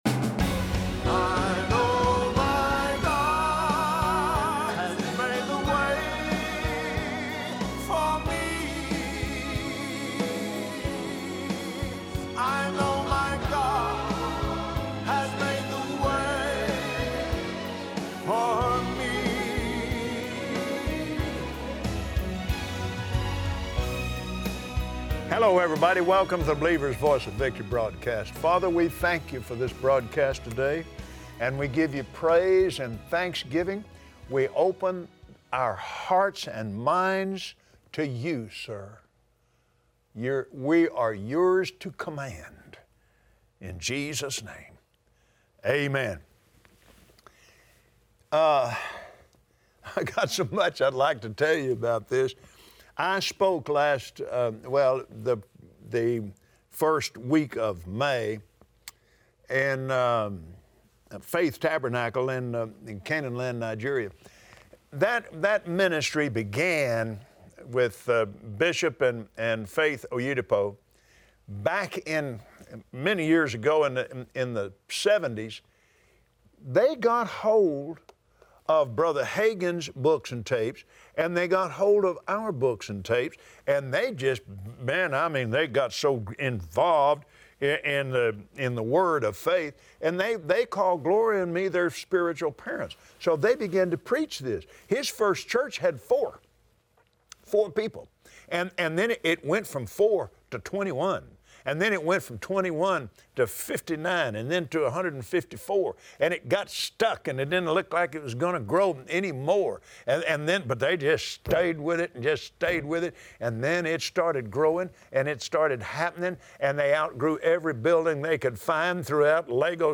Believers Voice of Victory Audio Broadcast for Tuesday 08/29/2017 Listen to Kenneth Copeland on Believer’s Voice of Victory share about the power of the love of God. Learn how believing in His love is the solid foundation for a life that is built to last.